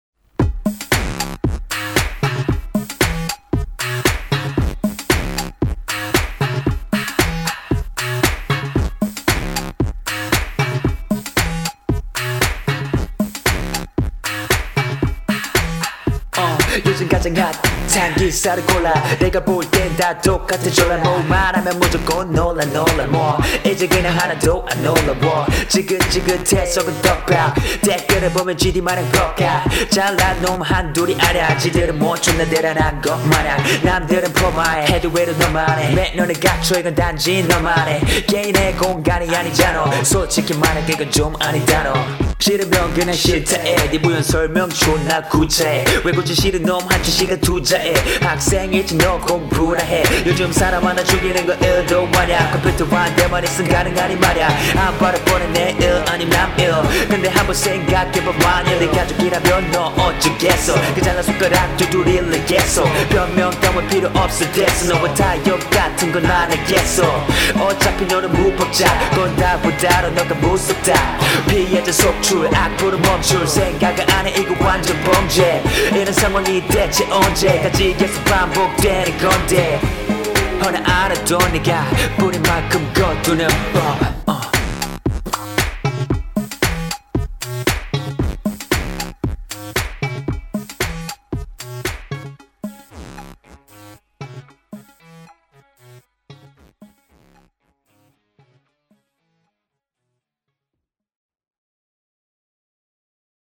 스타일이신지는 모르겠는데.. 라임배치가 계속 똑같아서 그런가 좀 지루하네요..